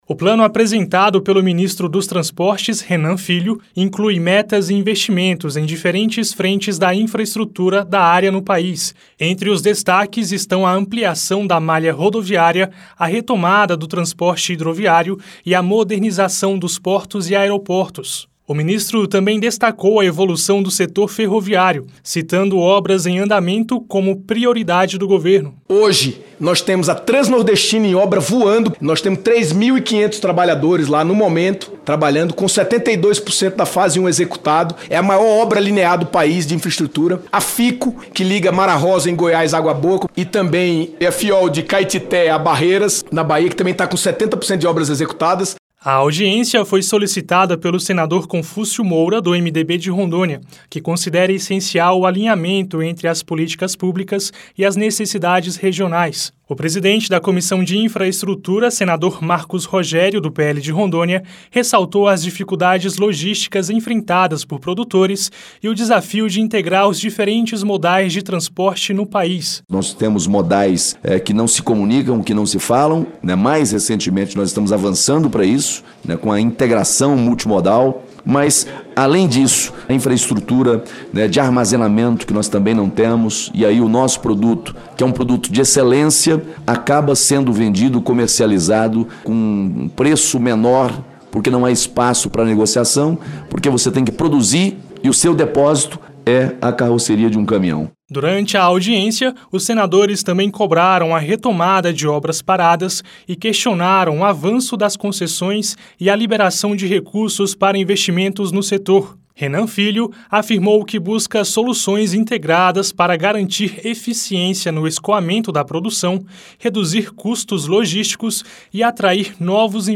O ministro dos Transportes, Renan Filho, apresentou nesta terça-feira (20) o plano de trabalho da pasta para os próximos dois anos em audiência na Comissão de Infraestrutura (CI).